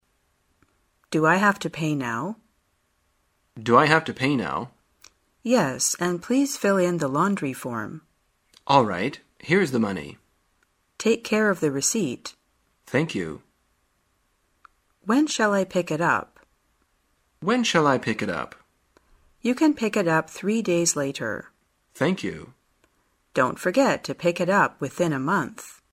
在线英语听力室生活口语天天说 第138期:怎样谈论付款和取衣时间的听力文件下载,《生活口语天天说》栏目将日常生活中最常用到的口语句型进行收集和重点讲解。真人发音配字幕帮助英语爱好者们练习听力并进行口语跟读。